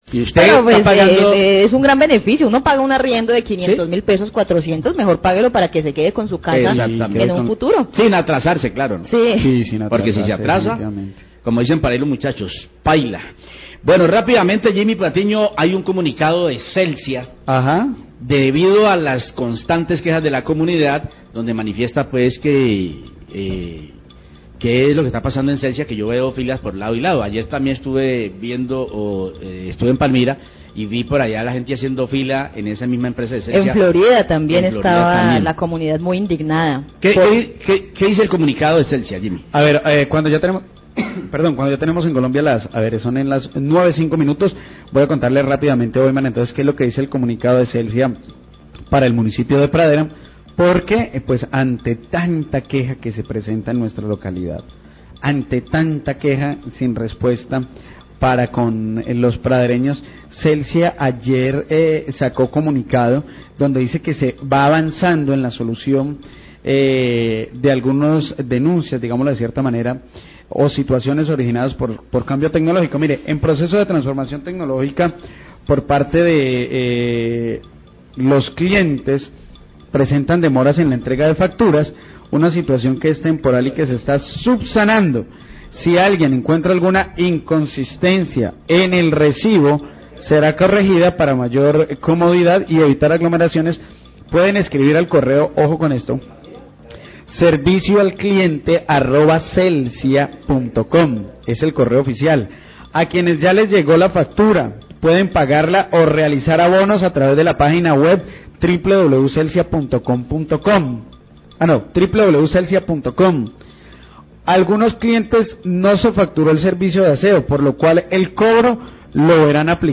Radio
Leen comunicado de prensa de Celsia explicando los altos costos que se han presentado en algunas facturas de energía en territorio del suroccidente del Valle. La periodista, afirma a titulo personal, que existe una pésima administración en esta empresa.